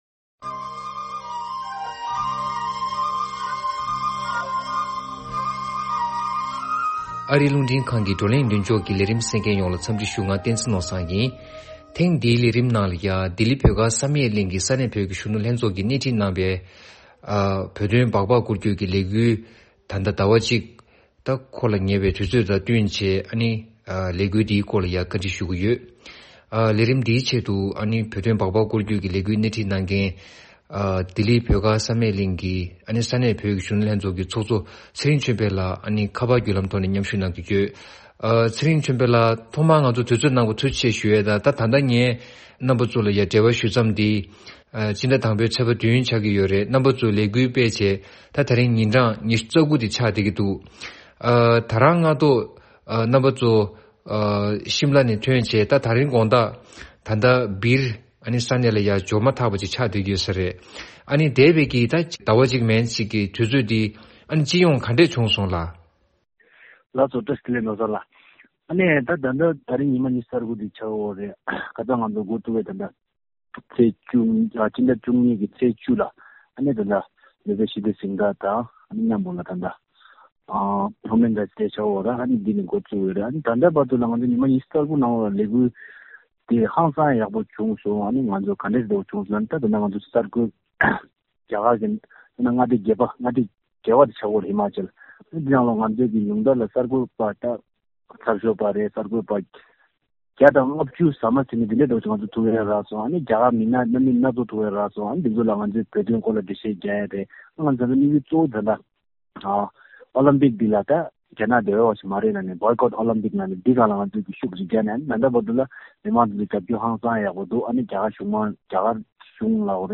ལྡི་ལི་ས་གནས་བོད་ཀྱི་གཞོན་ནུ་ལྷན་ཚོགས་ཀྱིས་རྒྱ་ནག་ཏུ་འཚོགས་རྒྱུའི་དགུན་དུས་ཨོ་ལེམ་པིག་རྩེད་འགྲན་ལ་མཉམ་ཞུགས་བྱ་རྒྱུ་མེད་པའི་འབོད་བསྐུལ་ཆེད་རྒྱ་གར་ལྷོ་ཕྱོགས་ནས་བྱང་ཕྱོགས་བར་བྷག་བྷག་སྐོར་སྐྱོད་ཀྱི་ལས་འགུལ་གཅིག་སྤེལ་ཡོད་པ་དེ་མཇུག་བསྒྲིལ་བྱས་ཡོད་པར་ལས་འགུལ་དེའི་ནང་མཉམ་ཞུགས་བྱེད་མཁན་ཚོར་གླེང་མོལ་ཞུས་ཡོད།